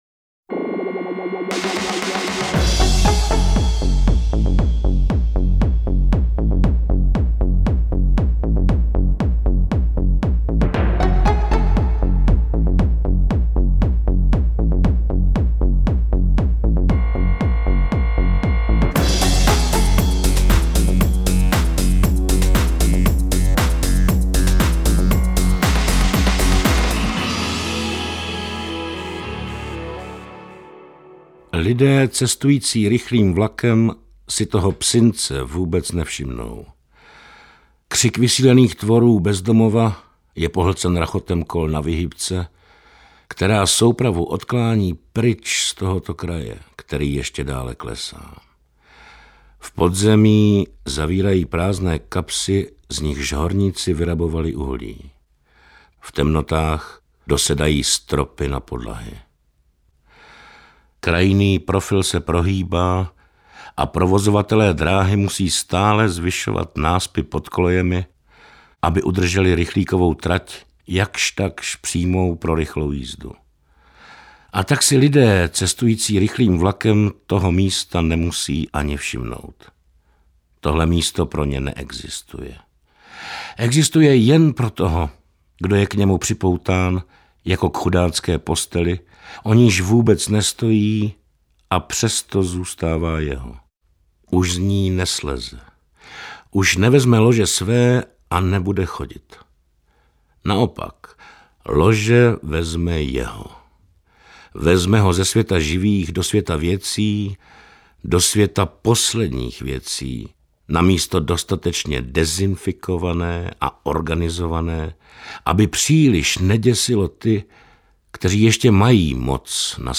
Interpret:  Norbert Lichý
Audioverze posledního, posmrtně vydaného románu Jana Balabána (1961-2010). Děj osciluje kolem jedné hlavní události - umírání a smrti otce tří dospělých sourozenců, jež vyvolá tok asociací, reflexí, vzpomínek, úvah, otázek a pochybností, které jsou velice často formulovány v dialozích.